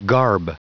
Prononciation du mot garb en anglais (fichier audio)
Prononciation du mot : garb